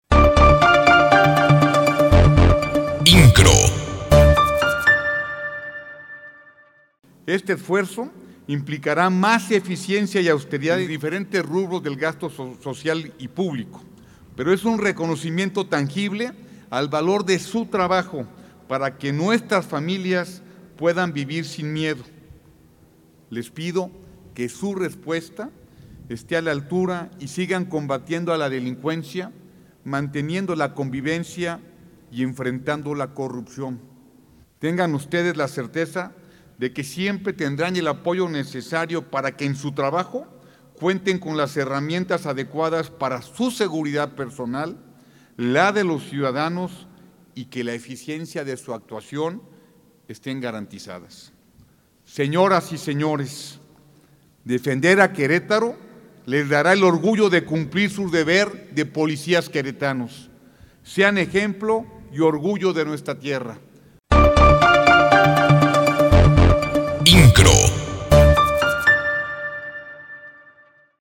En el marco de la entrega de equipamiento y unidades vehiculares a instituciones de seguridad y procuración de justicia de Querétaro, el gobernador, Mauricio Kuri González, anunció un aumento salarial para los cuerpos de seguridad del estado del 100 por ciento logrado para el resto de los trabajadores administrativos.